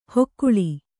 ♪ hokkuḷi